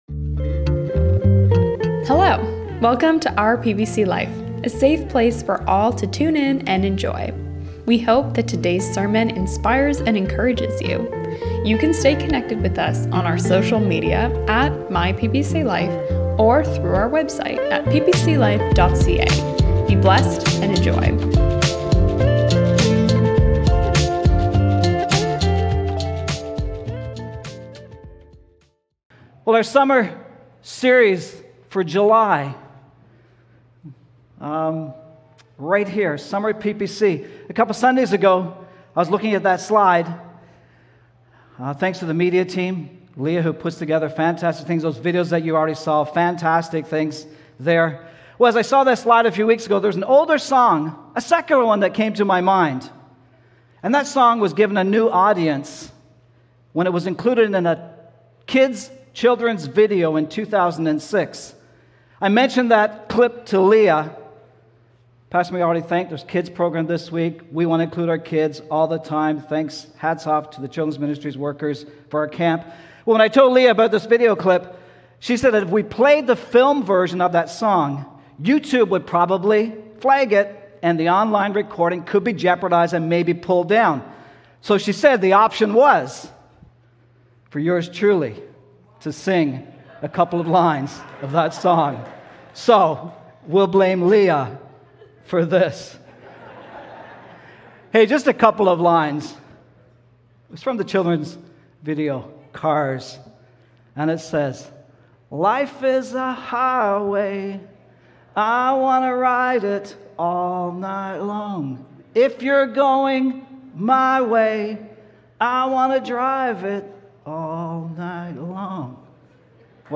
We trust you are enjoying our summer sermons!